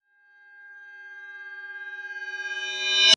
time_warp_reverse_high_06.wav